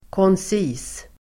Ladda ner uttalet
Uttal: [kåns'i:s]